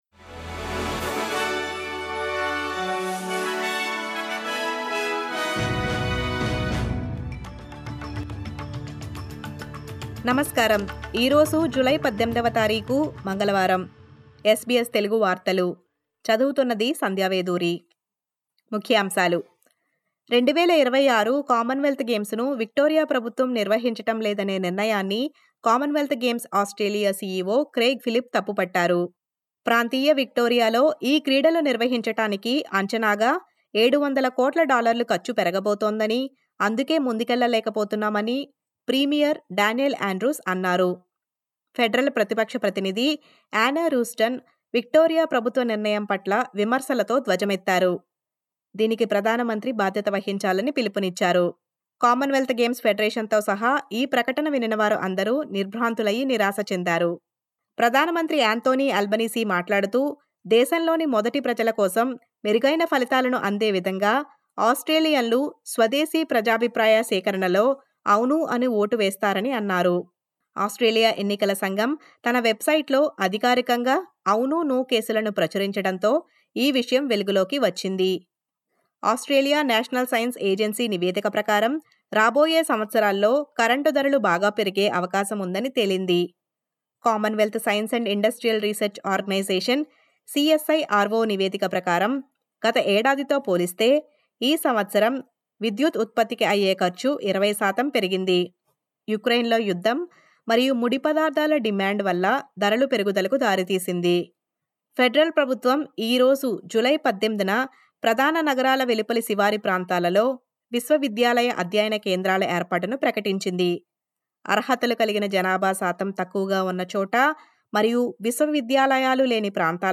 SBS తెలుగు 18/07/23 వార్తలు: 2026 కామన్ వెల్త్ గేమ్స్ ను విక్టోరియ ప్రభుత్వం నిర్వహించటం లేదు.